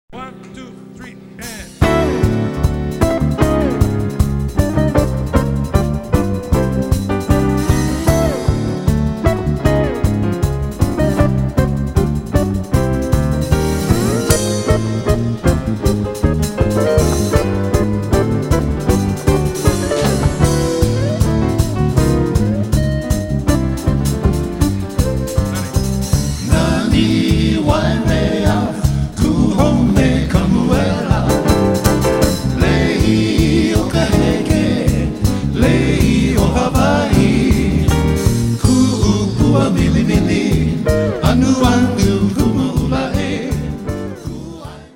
Genre: World music; hints of jazz; four-part harmonies.
old-style harmonics in the tradition of the Invitations